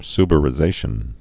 (sbər-ĭ-zāshən)